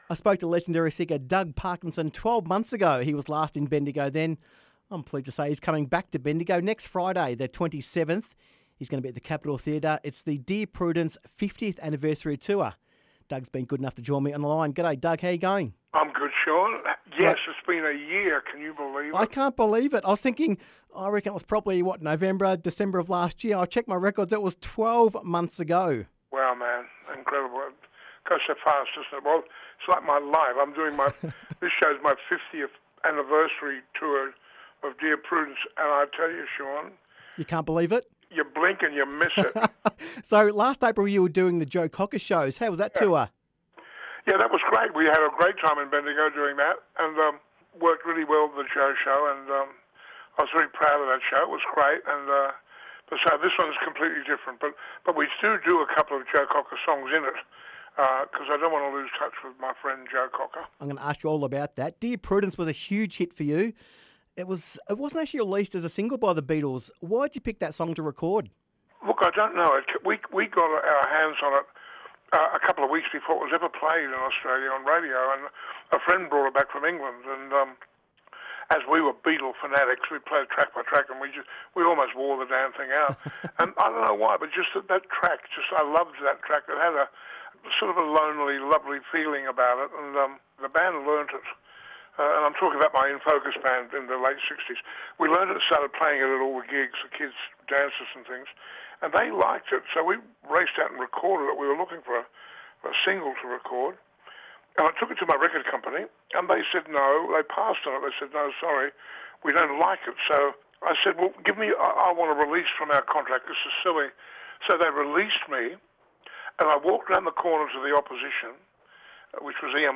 Doug Parkinson Interview 21/04/18